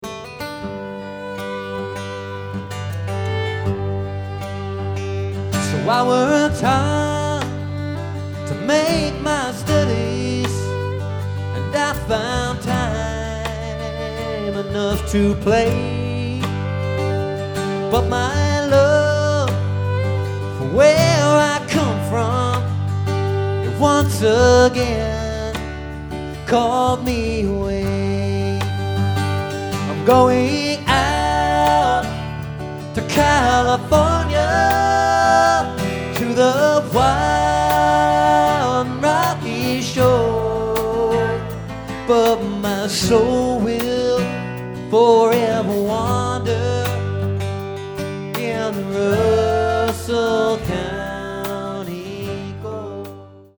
Mystic Theatre • Petaluma, CA